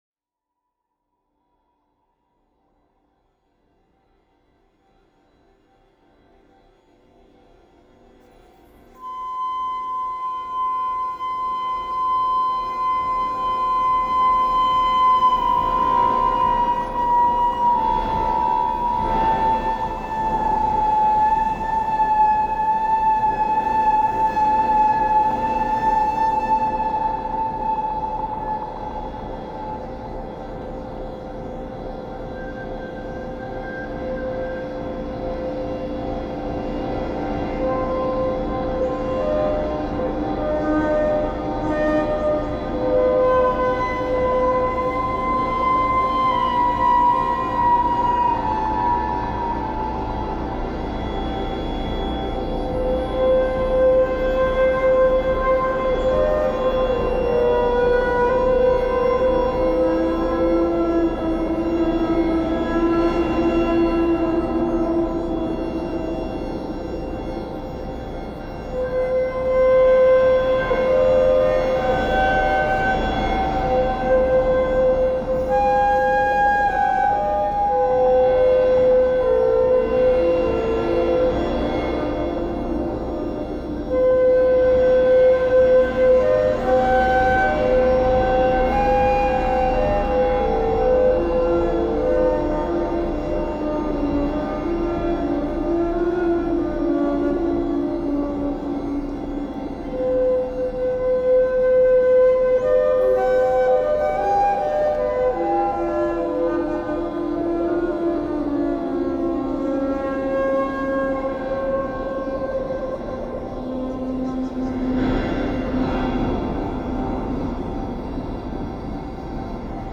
modular synthesizer
guitar
voice
accordion
flute and effects